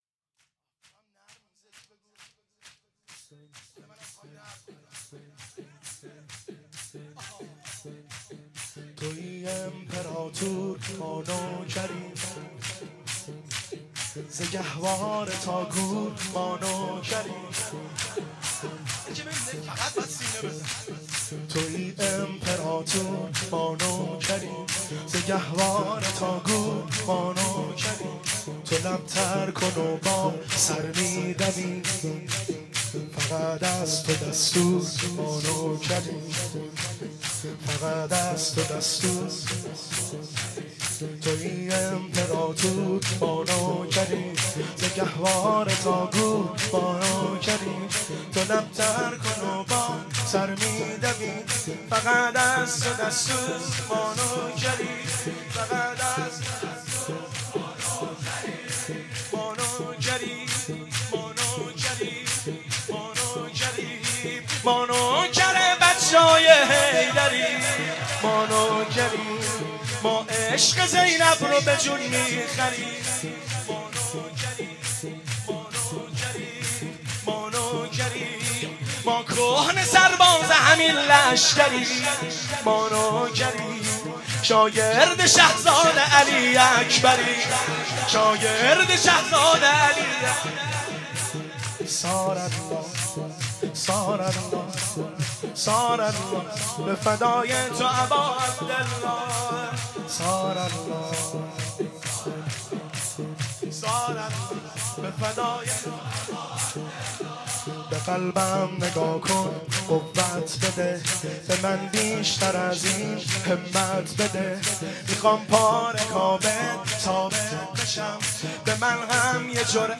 روضه و ذکر
Beytolahzan Kashan (12).mp3